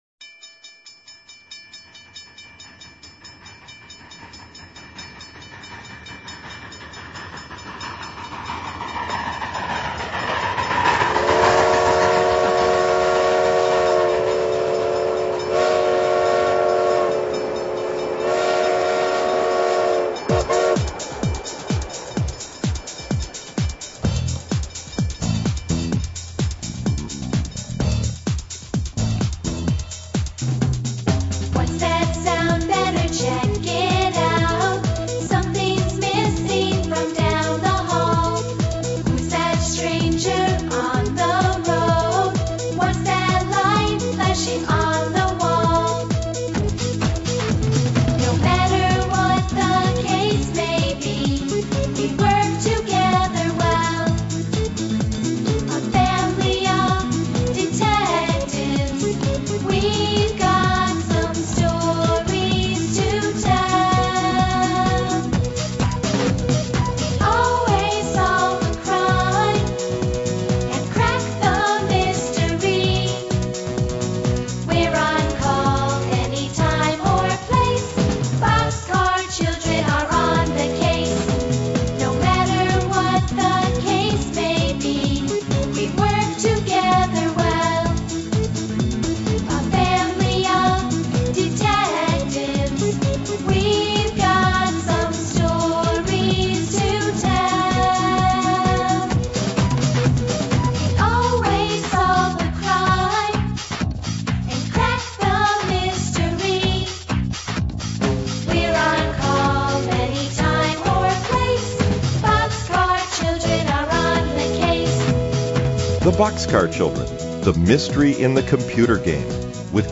Bug 12819 – Pop heard when sample rate changes
MP3 track with initial pop
Maybe created at 48KHz sample rate?